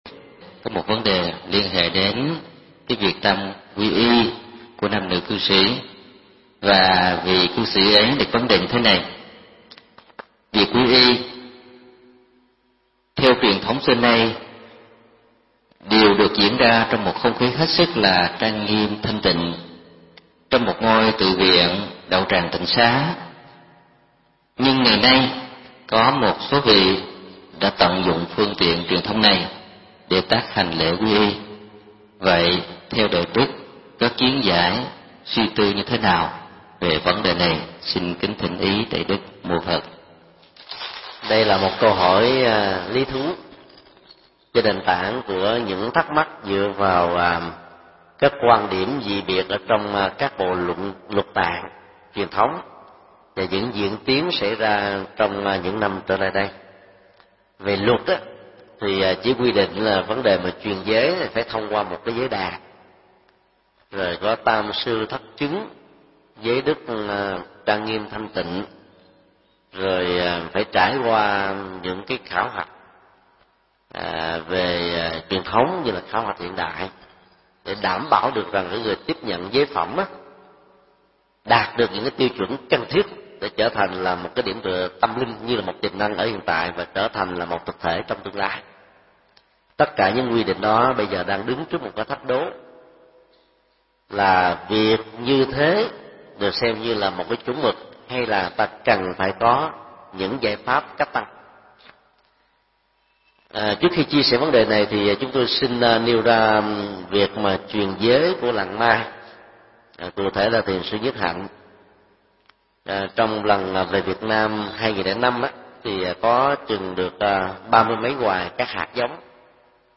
Mp3 Vấn đáp: Quy y và truyền giới trong cuộc sống hiện nay